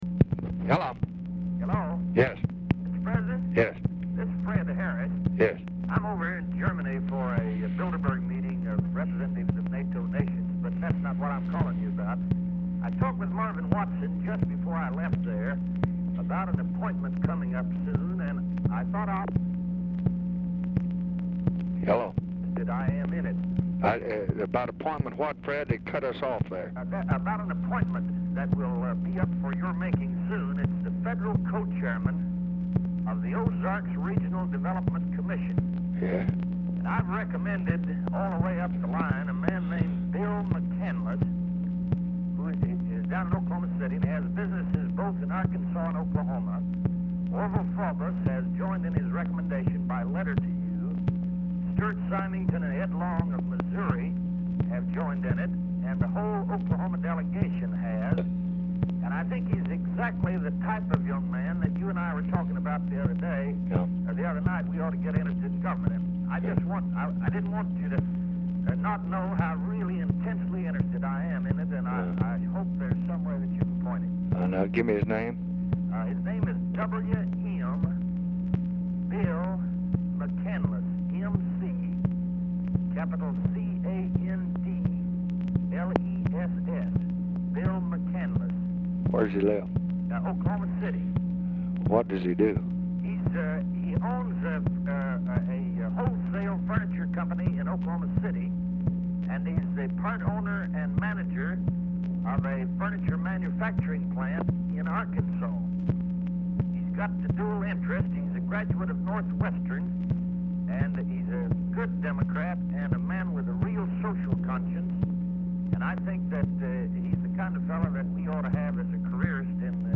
POOR SOUND QUALITY; HARRIS IS DIFFICULT TO HEAR
Format Dictation belt
Specific Item Type Telephone conversation